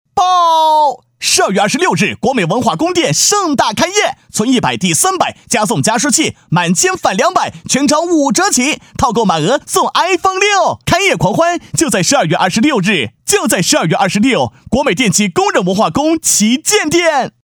促销配音是指配音员对产品促销广告词进行配音的过程，促销广告配音跟一般的电视广告配音不同，促销广告配音在风格上，一般男声配音都比较激情，女声配音欢快、时尚。
男声配音